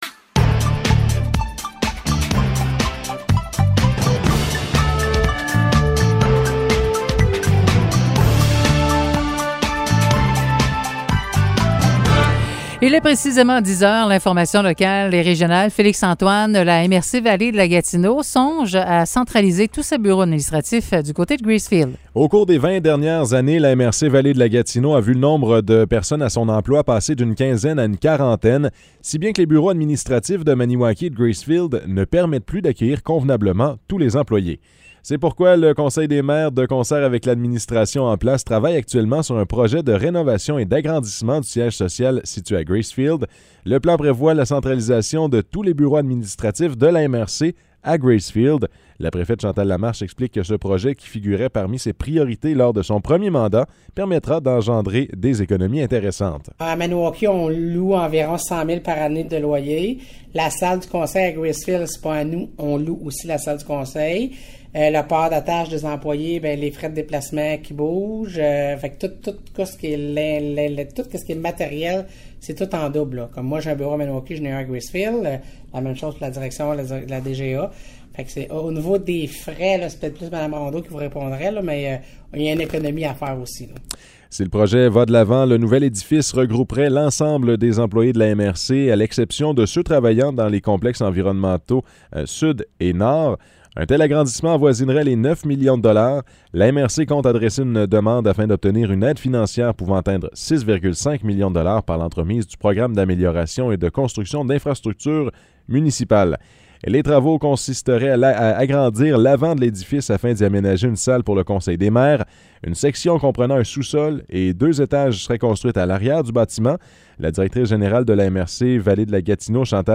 Nouvelles locales - 20 juin 2022 - 10 h